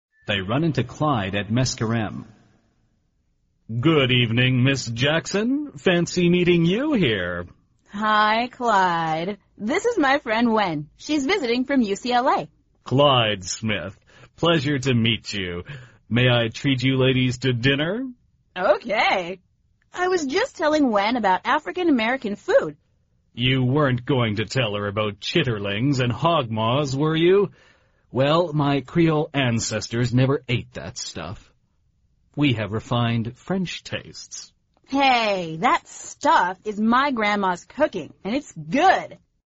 美语会话实录第237期(MP3+文本):French tastes!